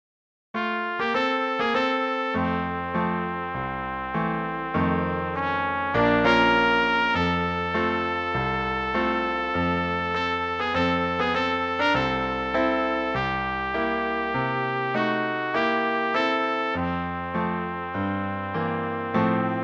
Swingová přednesová skladba pro trubku
trubka Ukázka MP3